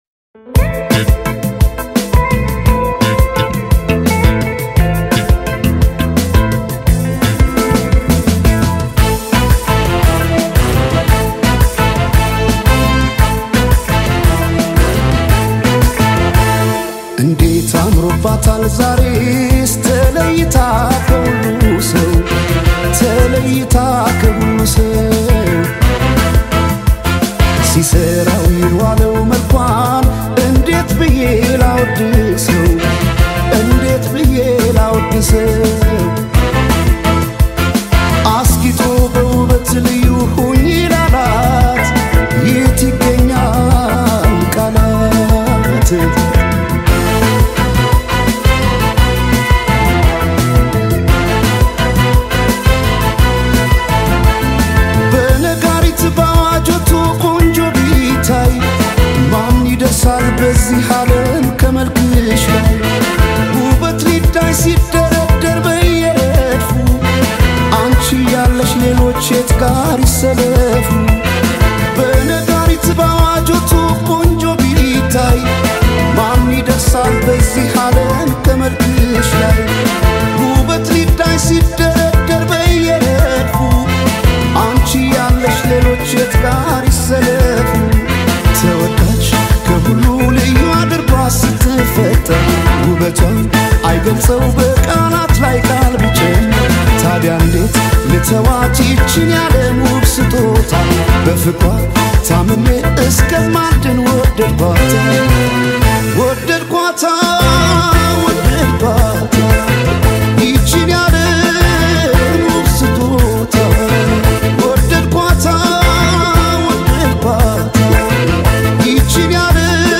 It keeps a steady rhythm that feels just right.